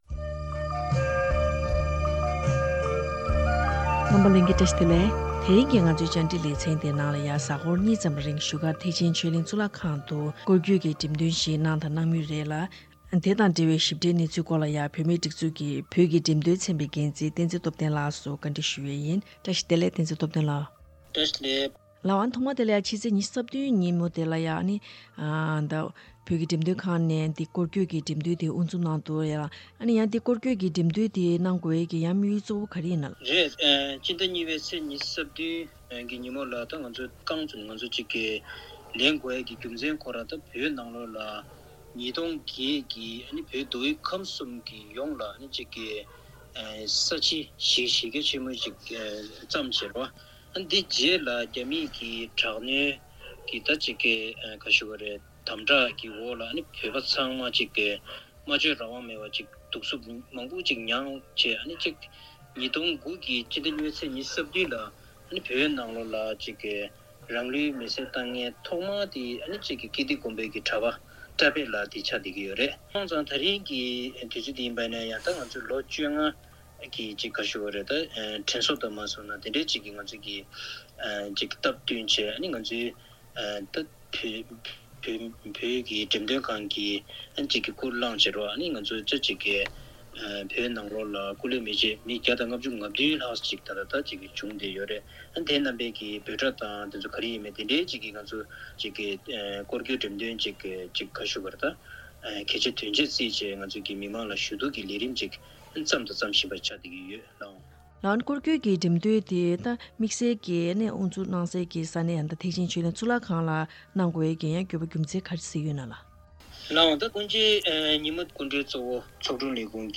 བཅར་དྲིའི་ལེ་ཚན